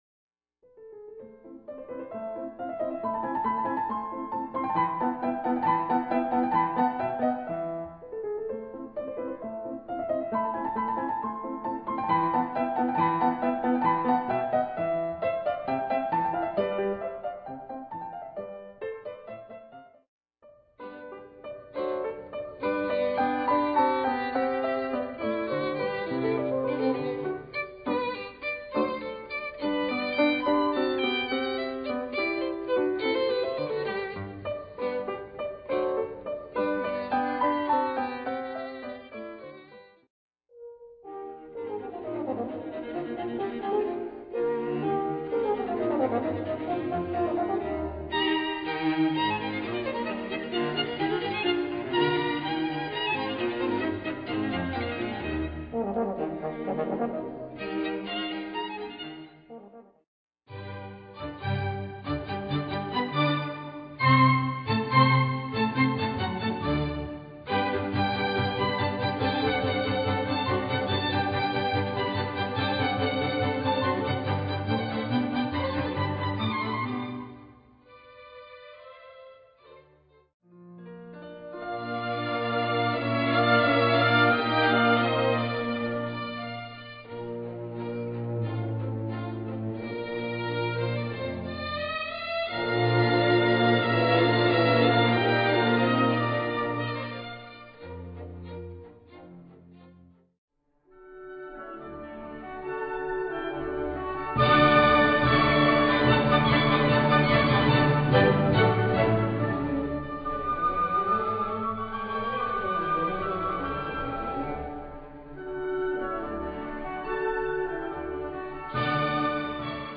Concerto for Flute, Harp and Orchestra